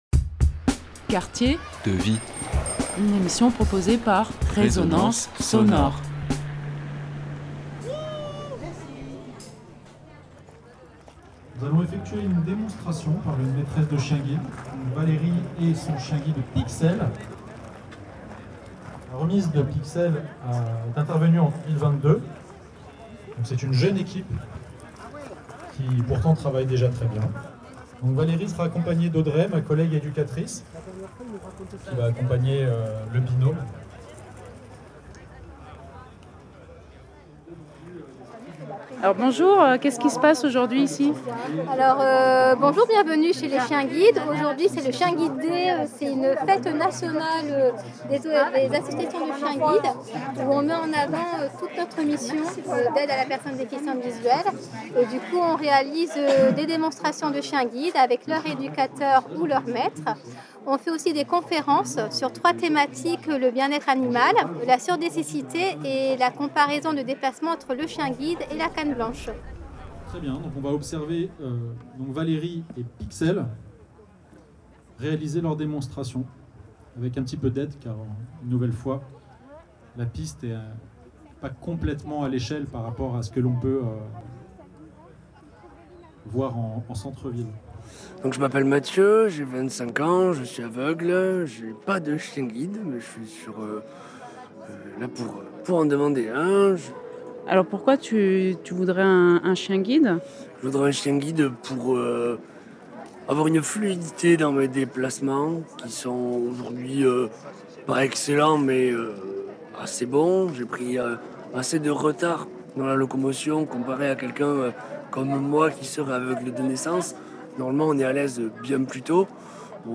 Chaque année, l’association des Chiens Guides organise dans toute la France des portes ouvertes, c’est l’occasion de découvrir le travail des chiens mais aussi de sensibiliser autour du handicap visuel. Nous les avons rencontré à Toulouse, le dernier week-end de septembre à cette occasion. Ce reportage vous fait découvrir comment les Chiens Guides arrivent dans la vie des personnes en situation de handicap visuel à travers de multiples témoignages: personnes bénéficiant d’un chien guide, éducatrice canine, famille d’accueil, bénévoles et professionnels de l’association.